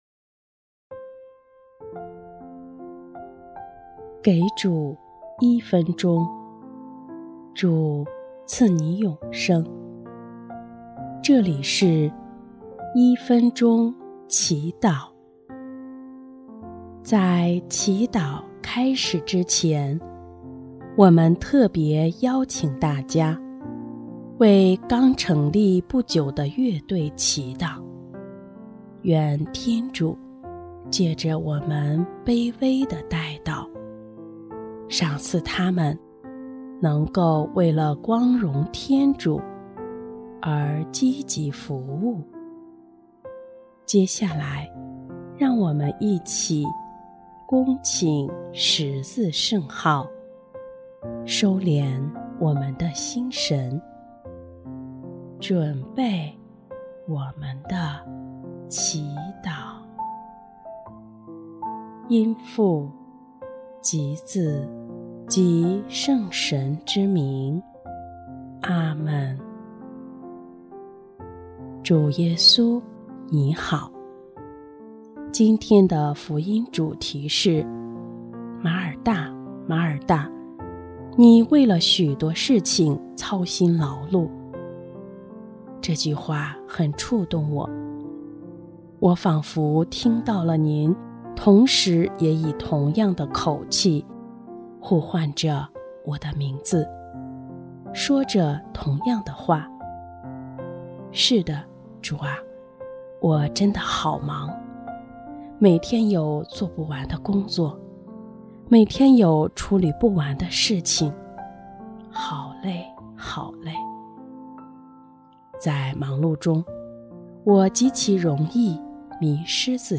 【一分钟祈祷】| 7月29日 在祂内，一切都有价值
音乐